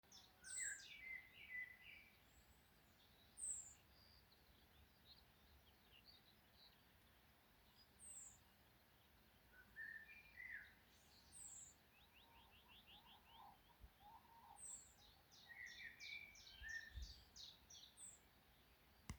Птицы -> Овсянковые ->
обыкновенная овсянка, Emberiza citrinella